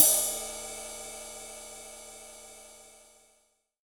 • Reverb Crash Single Shot D# Key 04.wav
Royality free crash cymbal sample tuned to the D# note. Loudest frequency: 9549Hz
reverb-crash-single-shot-d-sharp-key-04-GjP.wav